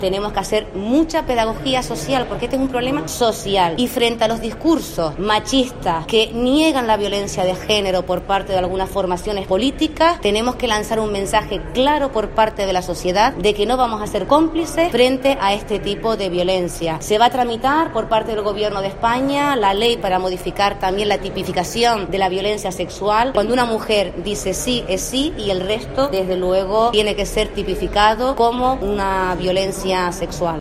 Elena Mañez, consejera de Economía y Conocimiento